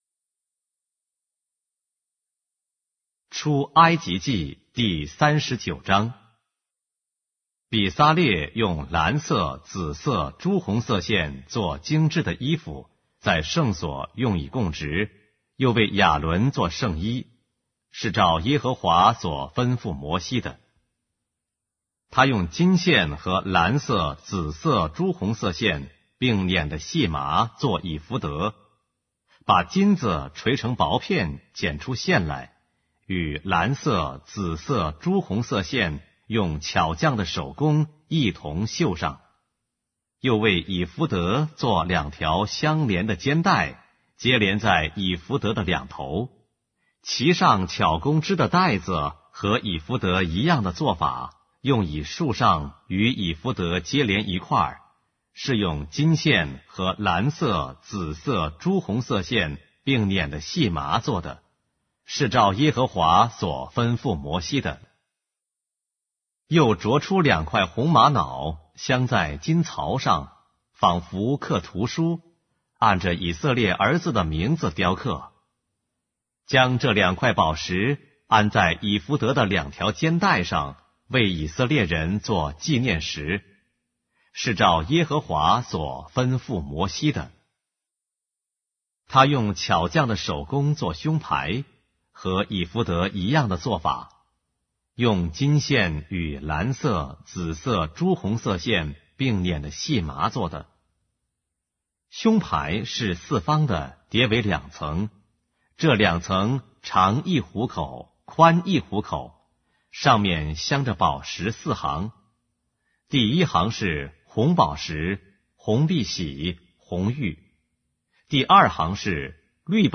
【经文中文朗读】